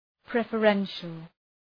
Προφορά
{,prefə’renʃəl}